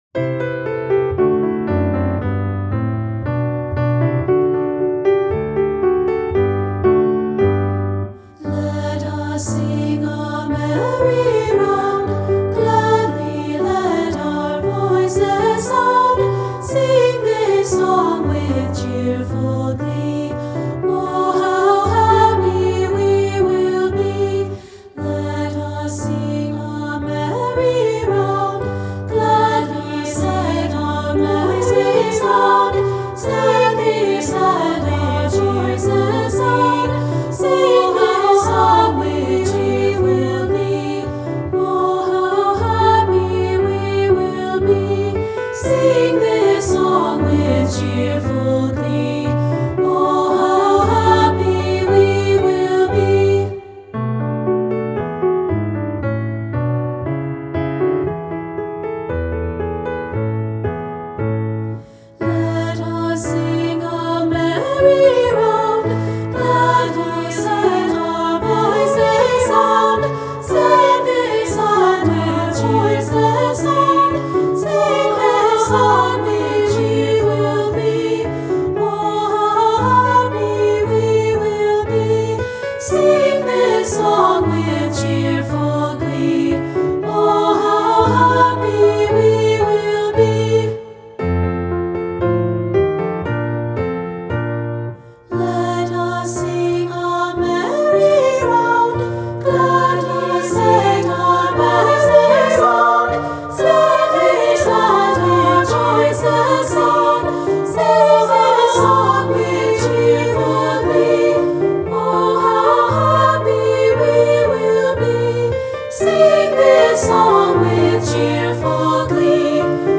Choral Concert/General